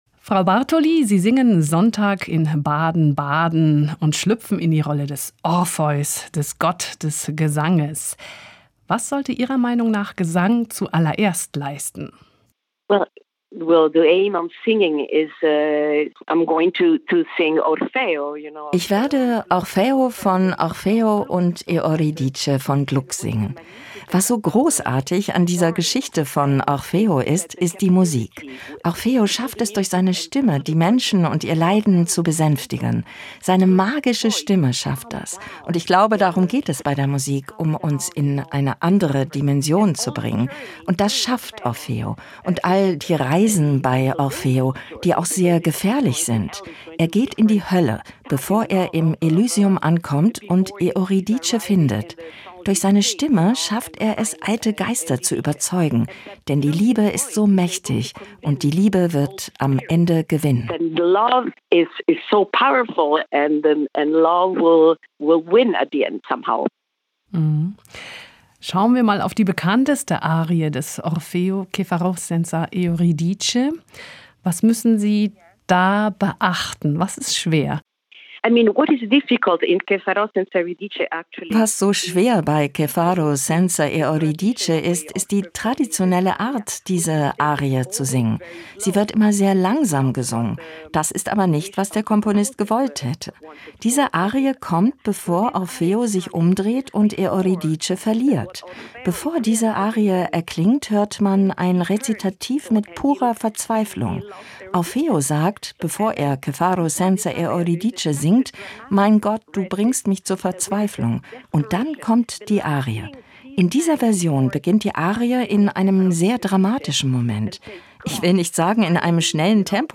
Die Mezzosopranistin gastiert am 24. November im Festspielhaus Baden-Baden mit Christoph Willibald Glucks Oper „Orfeo ed Euridice“. Im Gespräch mit SWR Kultur erzählt sie, was sie an ihrer Orfeo-Rolle fasziniert und warum sie bei den Chören von Gluck das Gefühl hat…